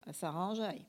Bois-de-Céné
Catégorie Locution